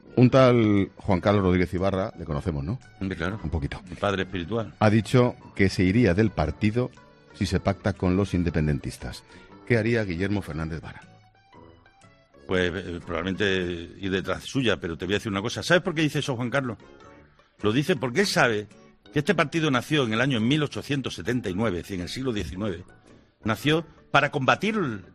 Declaraciones de Guillermo Fernández Vara